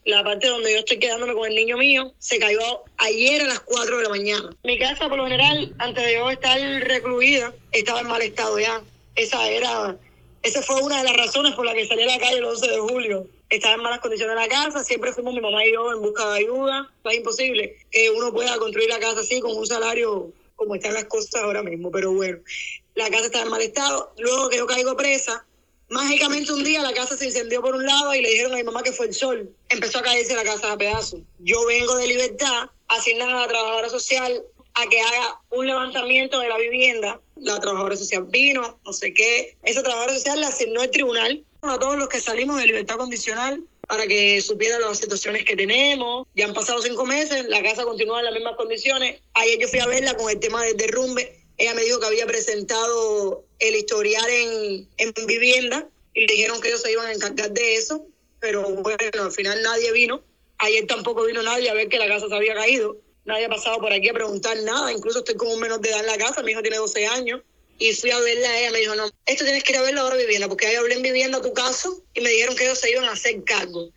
Testimonio